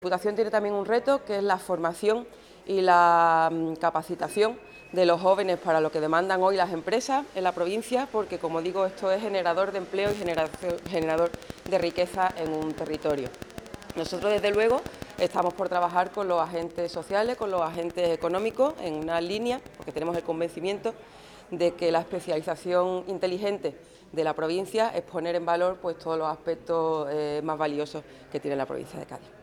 La presidenta de Diputación, Almudena Martínez, ha intervenido en la inauguración de la jornada ‘Transformación digital’ que organiza Comisiones Obreras, CCOO, con cargo al programa DipuActiva.